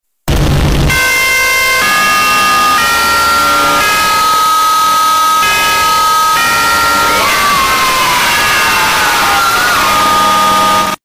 Grasshopper Alarm Bouton sonore
Memes Soundboard4 views